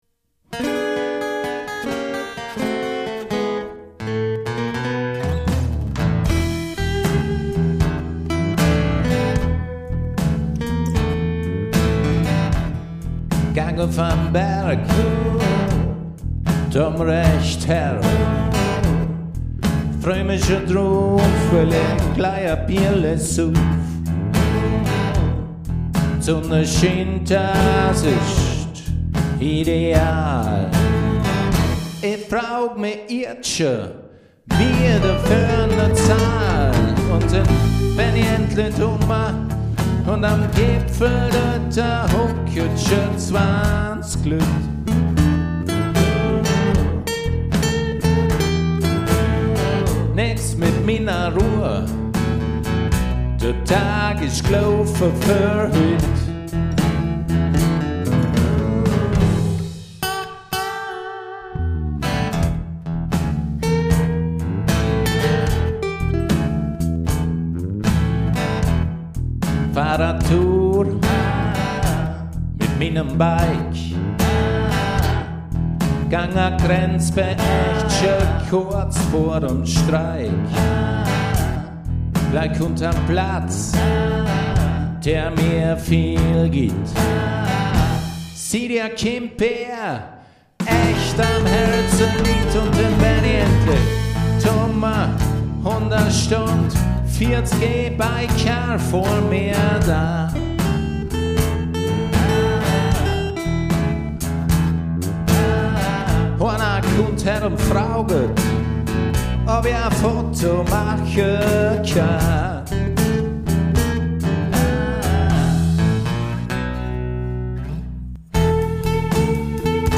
Handgemachte Musik im Bregenzerwälder-Dialekt